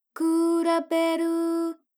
ALYS-DB-002-JPN - Source files of ALYS’ first publicly available Japanese vocal library, initially made for Alter/Ego.